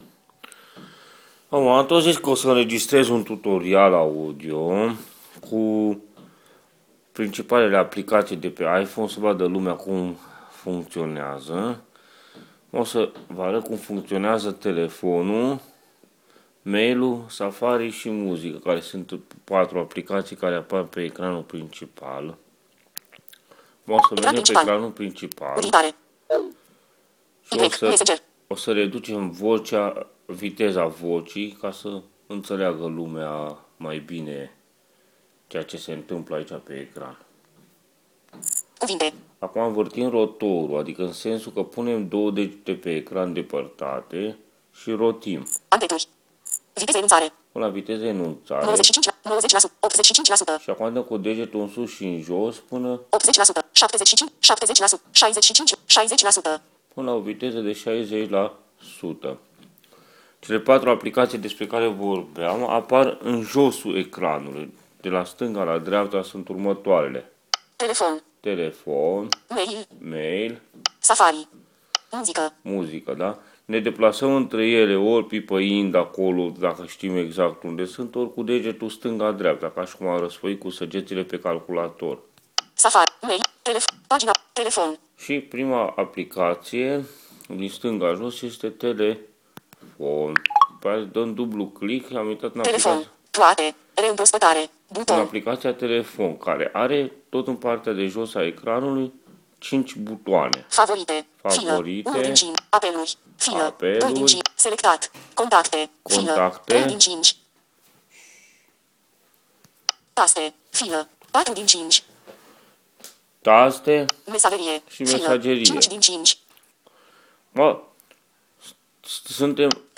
inregistrarea a fost facuta chiar cu el. din pacate inregistrarea s-a intrerupt cand am pornit muzica lucru pe care eu lam uitat.
Tutorial_01_-_Utilizare_iPhone_telefon_mail_internet.m4a